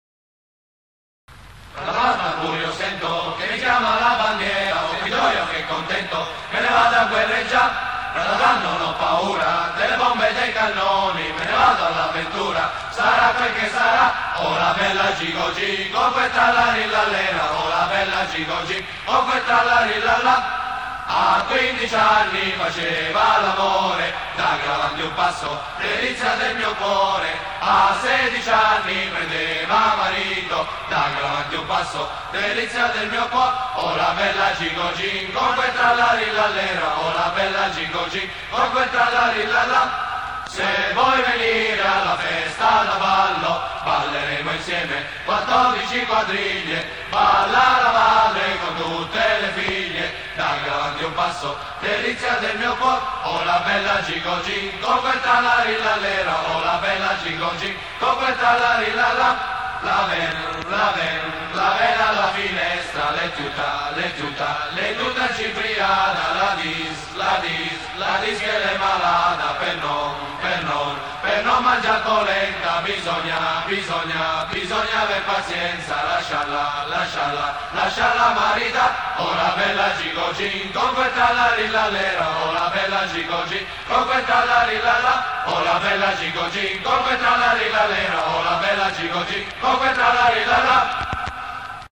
la bella gigogin cantata.mp3